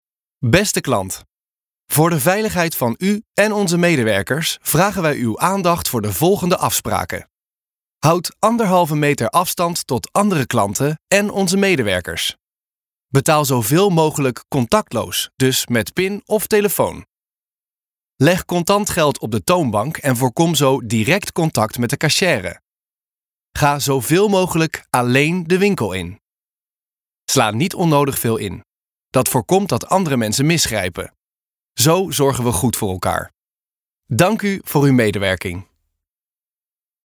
Kommerziell, Vielseitig, Zuverlässig, Freundlich, Warm
Telefonie
His voice is often described as warm and approachable. It is not a standard voice-over sound, but rather a sound that stands out without dominating. Think of a fresh, mature voice with that typical millennial sound: clear, accessible and fresh.